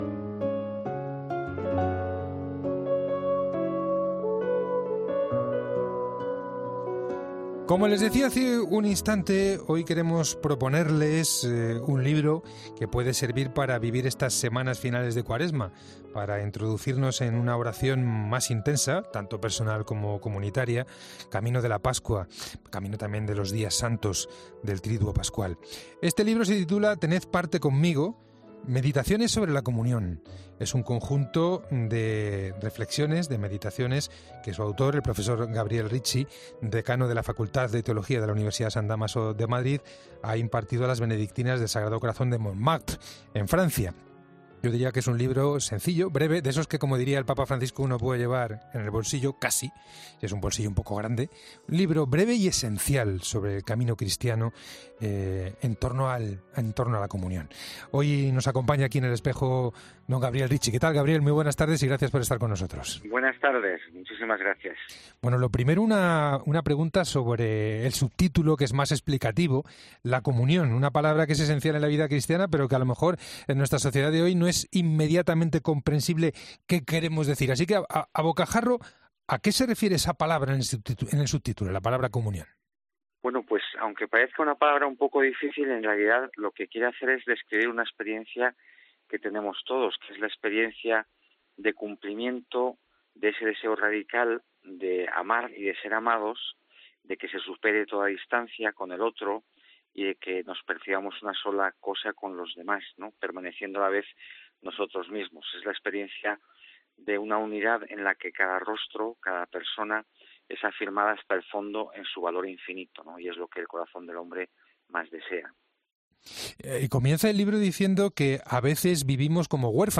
ha atendido a los micrófonos de El Espejo de la Cadena COPE